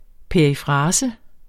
Udtale [ peɐ̯iˈfʁɑːsə ]